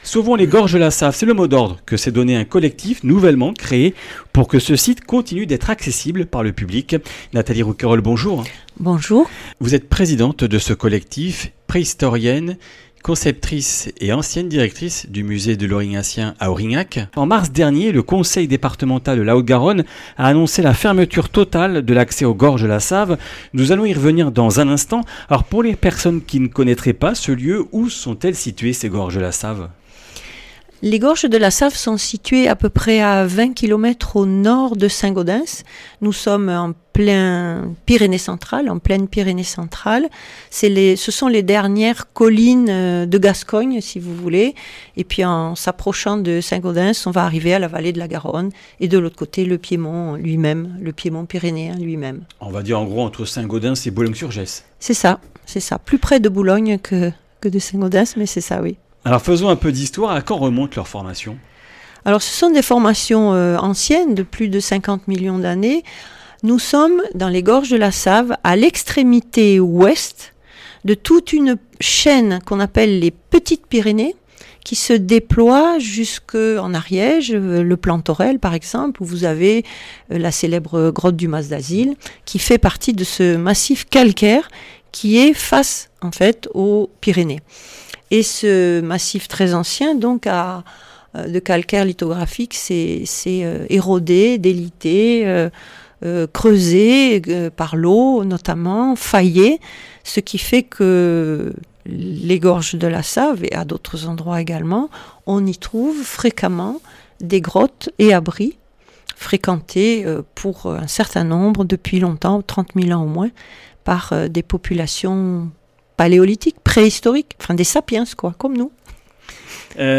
Comminges Interviews du 14 oct.